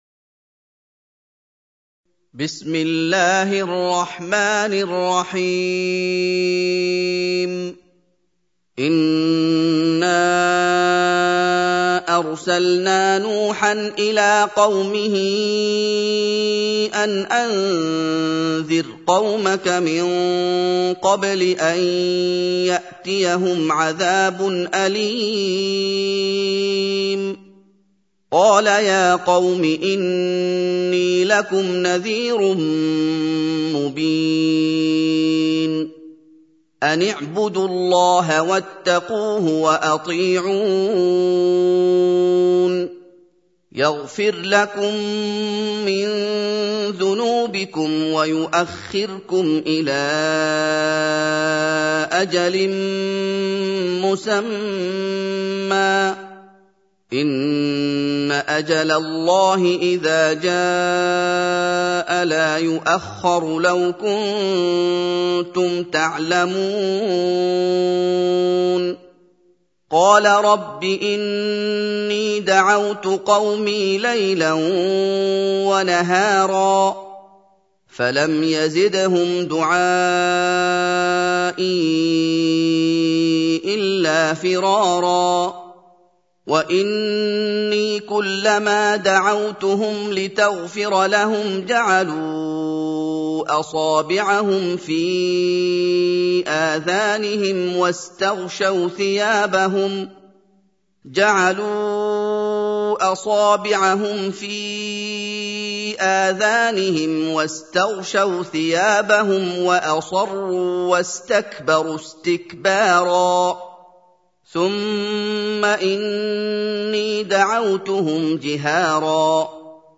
سورة نوح | القارئ محمد أيوب